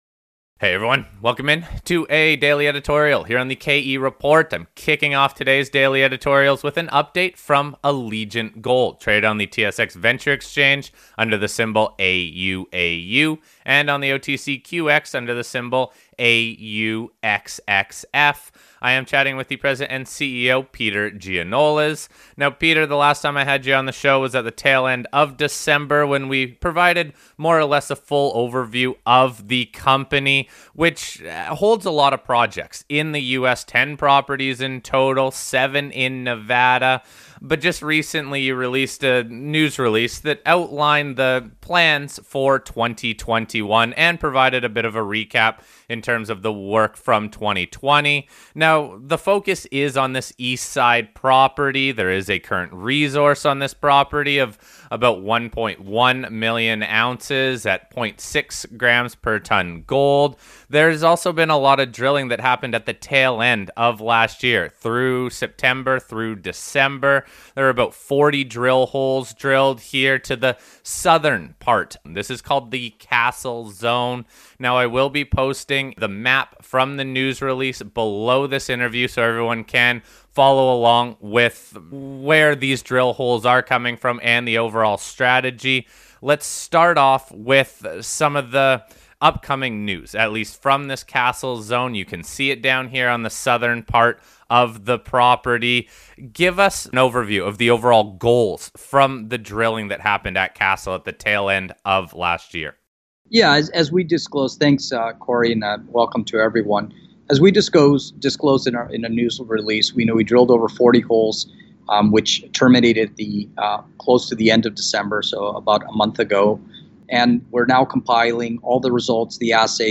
Allegiant Gold – An in-depth discussion on the 2021 exploration plans for the Eastside Property – Korelin Economics Report